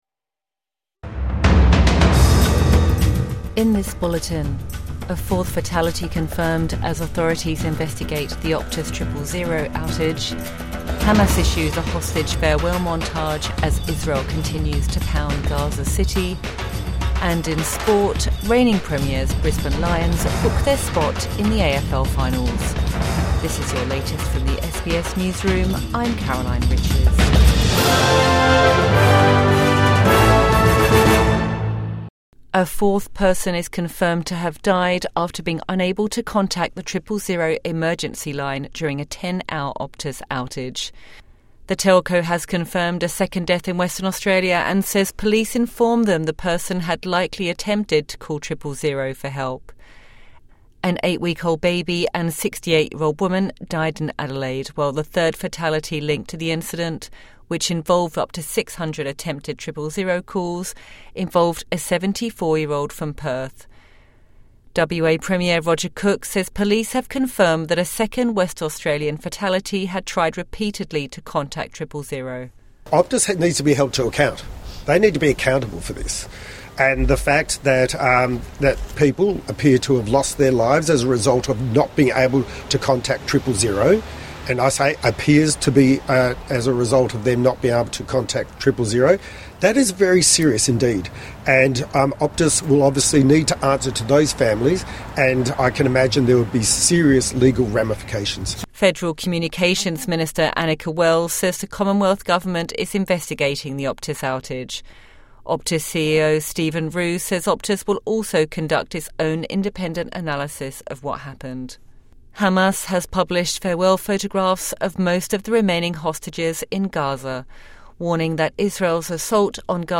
A fourth fatality confirmed in the Optus Triple-Zero outage | Morning News Bulletin 21 September 2025